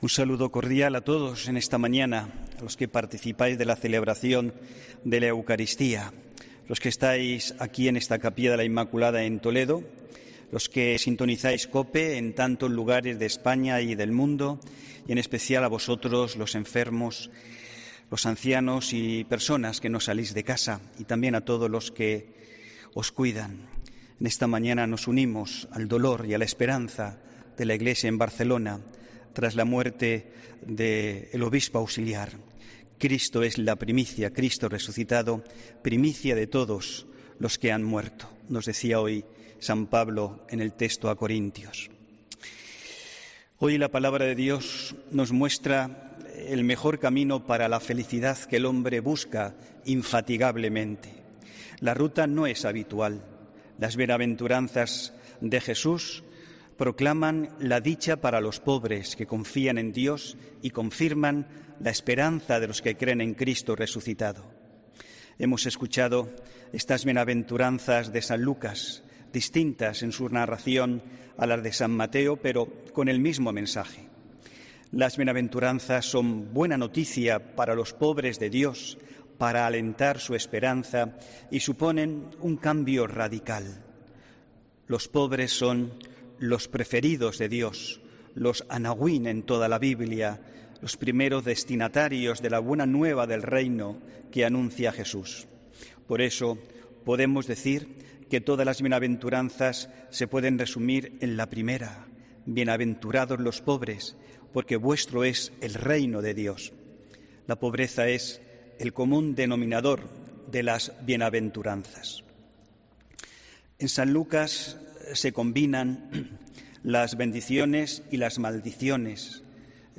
HOMILÍA 13 FEBRERO 2022